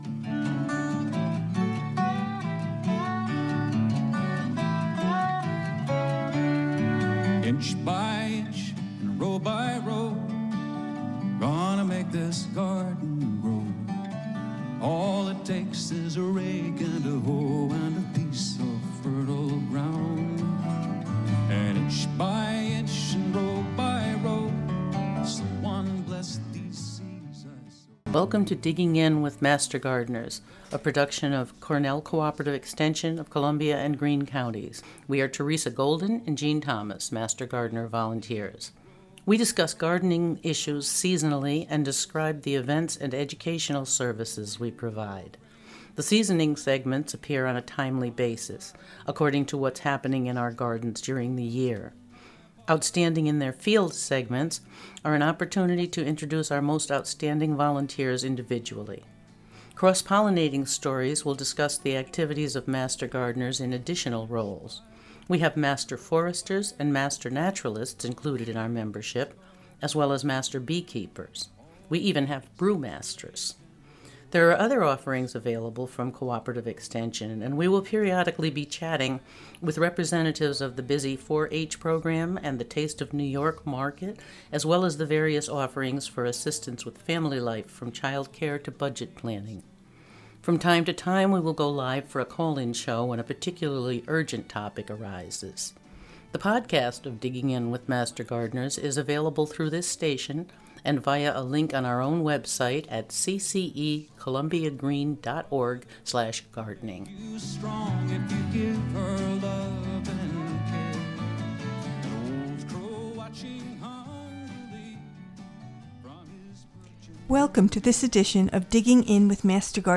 A conversation about gardening issues seasonally,...